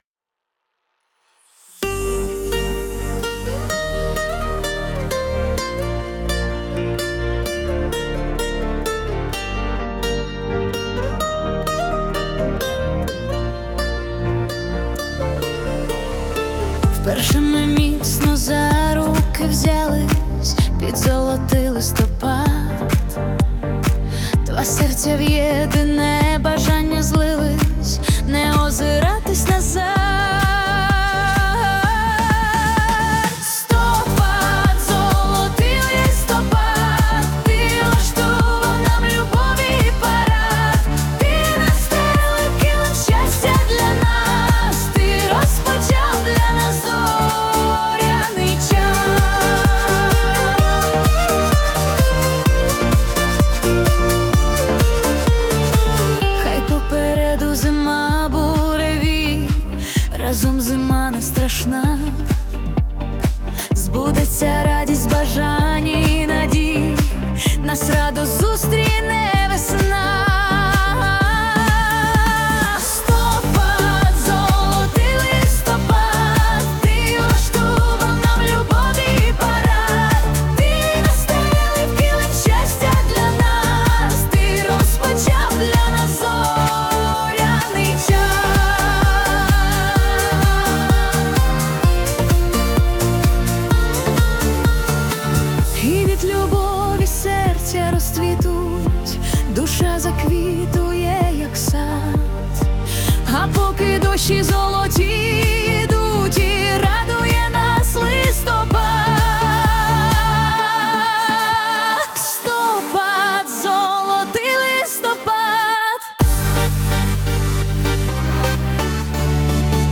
12 osen2 Цікава, чуттева пісня кохання! 42 heart 43